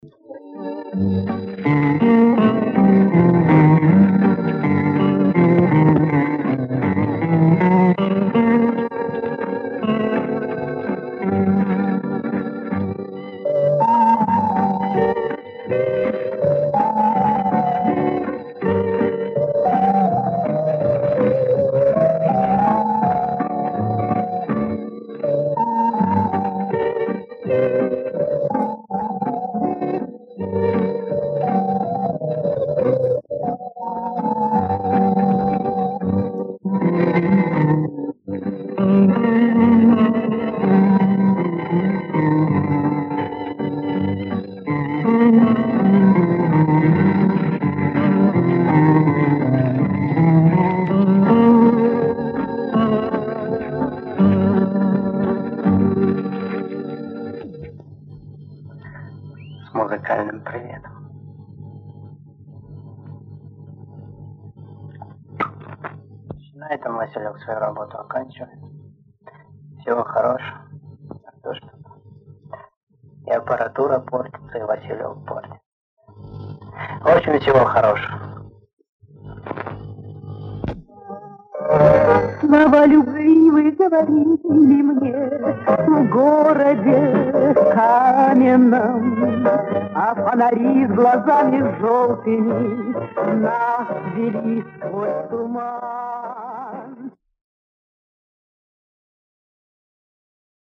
Позывной "ВАСИЛЕК" В конце 60х в Лисичанске очень хорошо проходила любительская станция под этим позывным.Предположительно из Часов-Яра.Отличалась очень хорошей музыкой и красивыми комментариями -в отличии от многочисленной братвы населяющей тогдашнийсредневолновый эфир.
Классная инструменталочка!